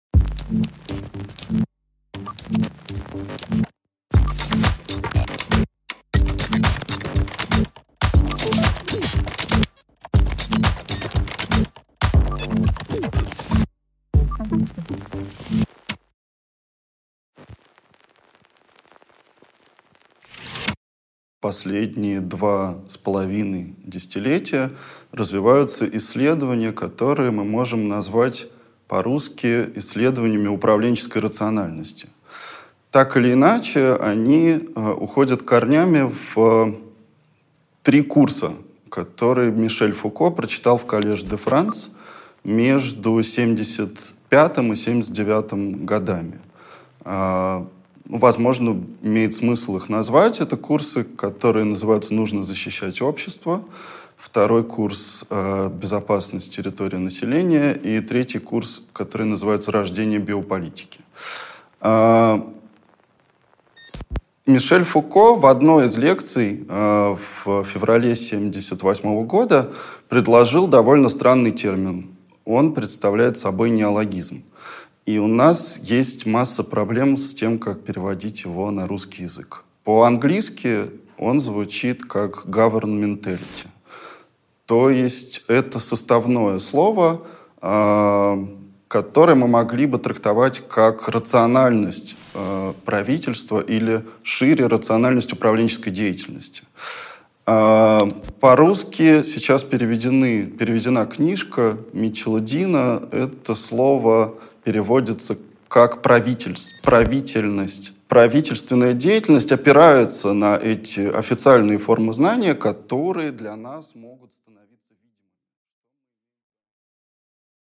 Аудиокнига Как управляется Европа | Библиотека аудиокниг